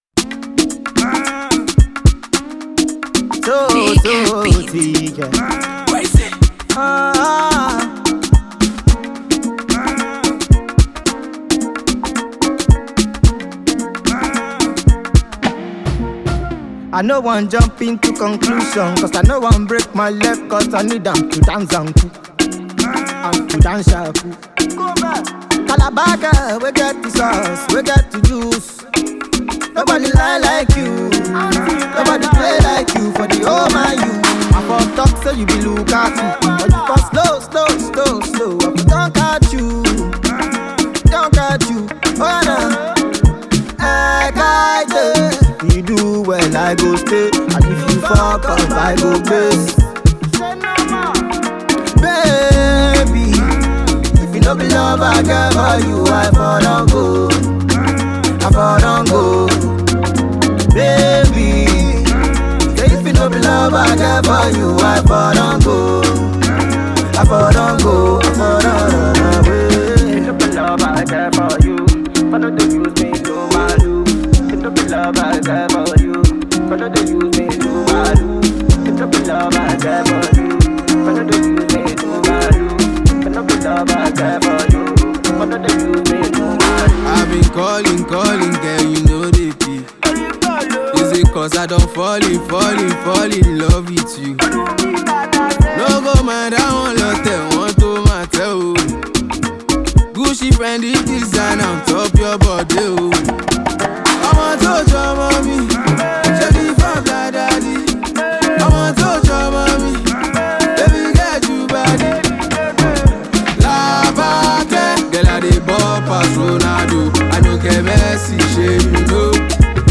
Soul/Pop fusion